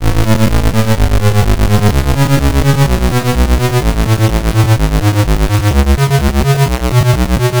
Lo-Bit Sixteens F 126.wav